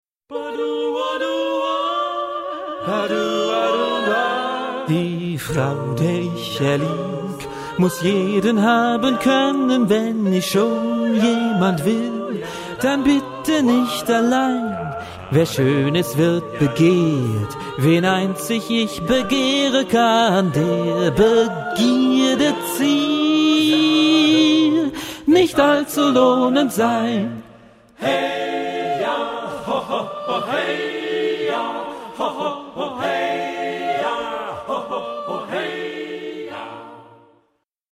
SATB (4 voices mixed) ; Choral score.
Choral jazz. Partsong.
Blues ...
Mood of the piece: melodious ; jazzy ; humorous
Tonality: B flat major